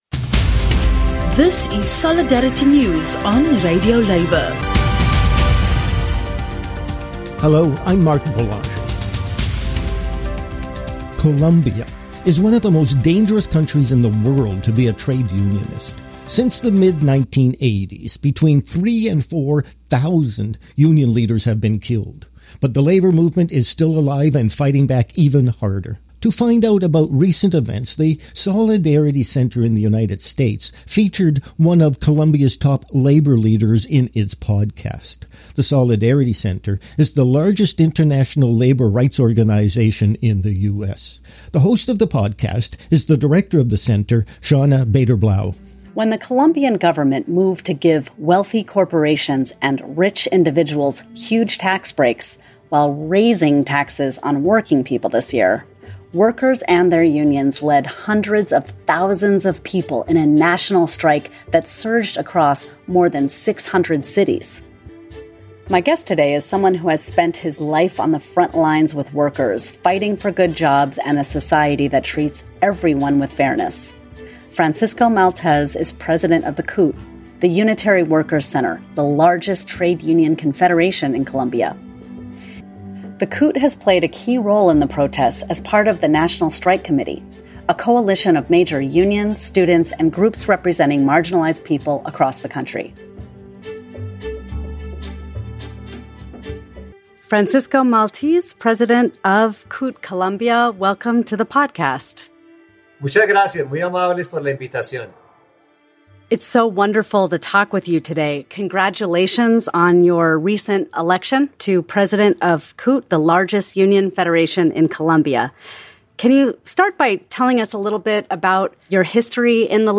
Unions in Colombia are fighting for justice for working people / An interview